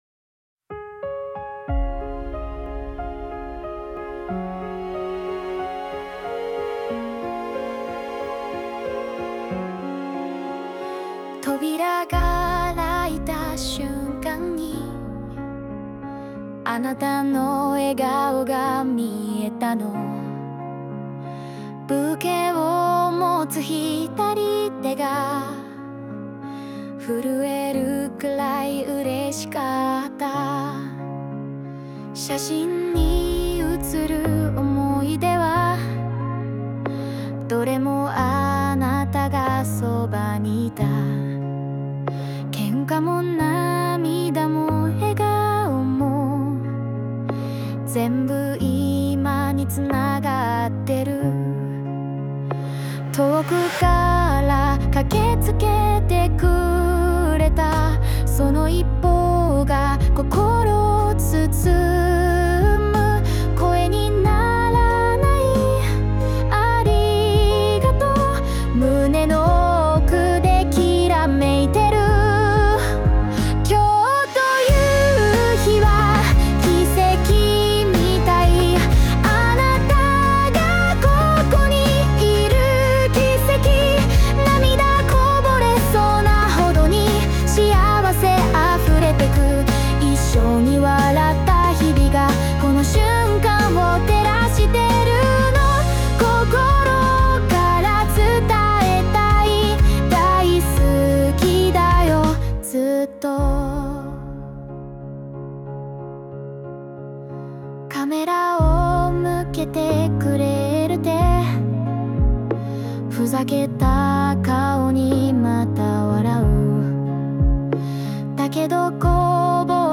著作権フリーオリジナルBGMです。
女性ボーカル（邦楽・日本語）曲です。